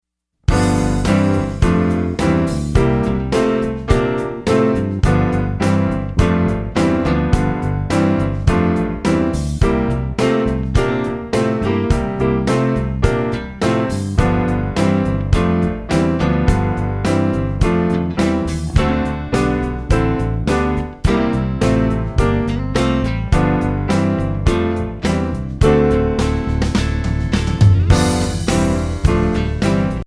Voicing: Piano Method